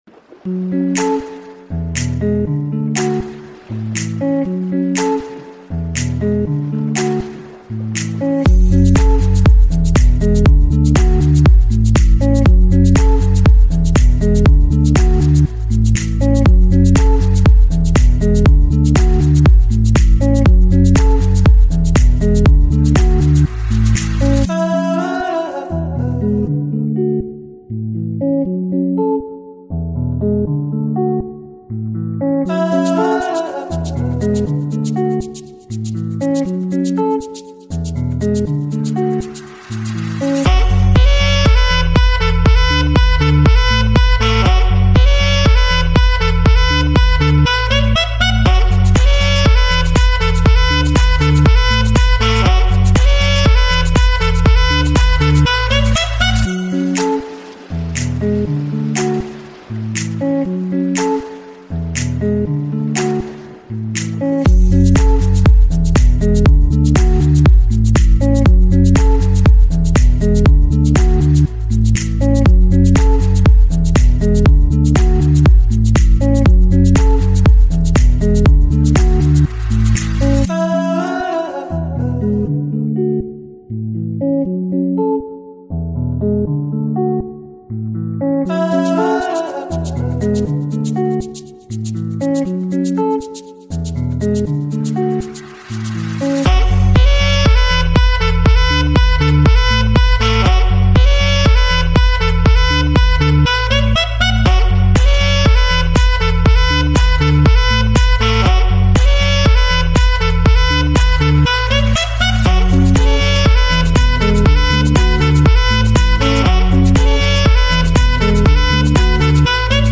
Música-Instrumental.mp3